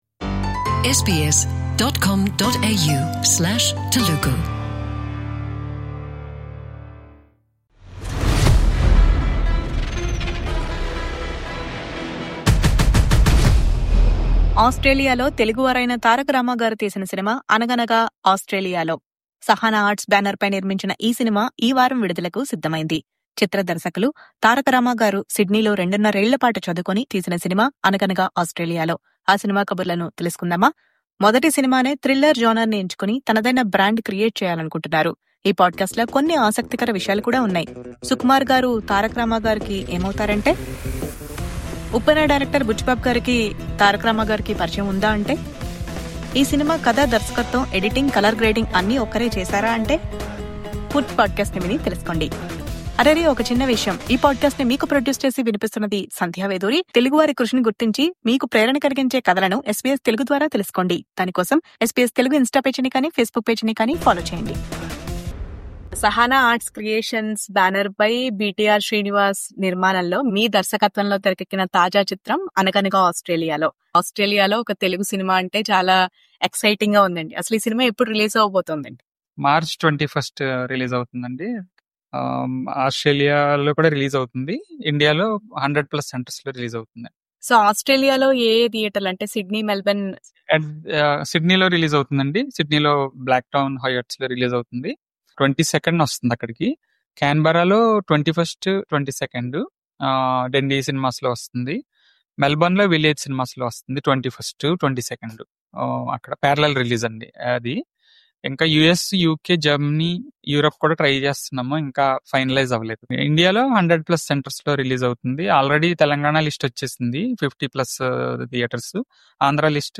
Exclusive interview with the director on SBS Telugu.